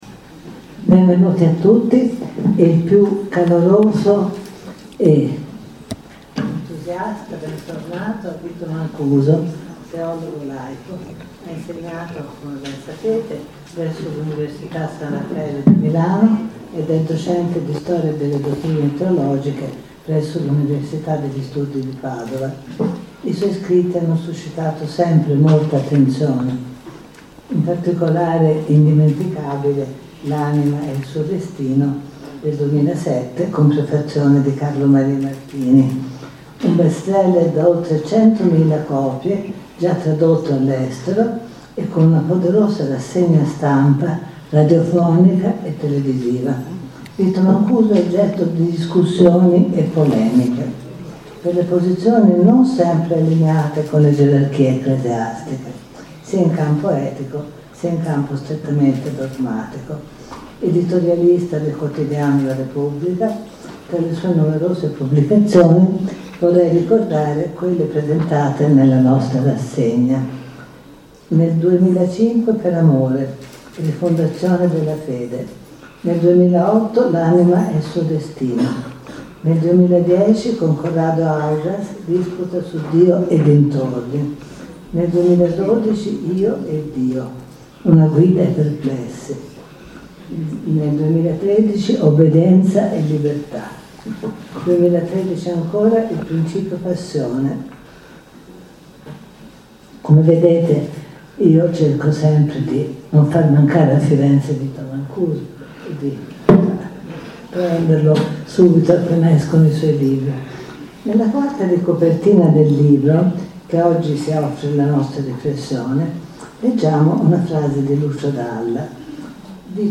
Numeroso il pubblico in sala